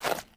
MISC Concrete, Foot Scrape 06.wav